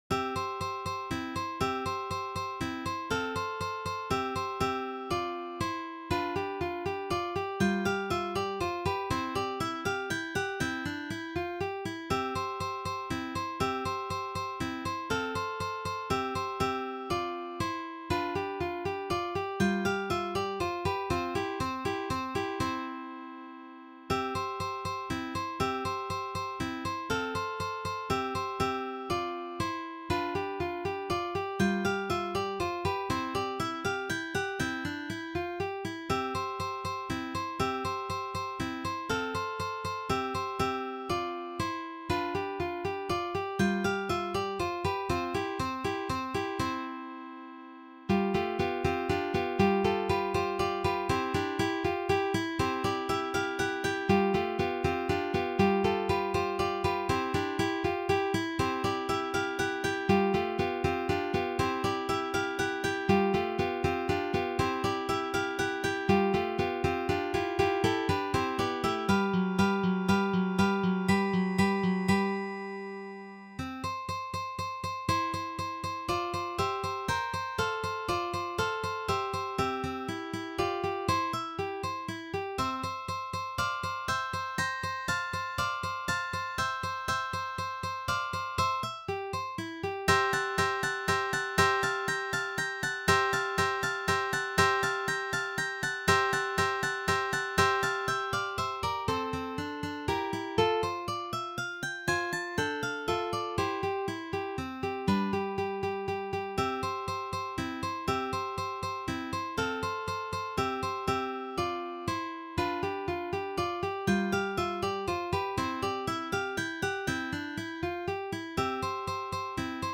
arrangement for three guitars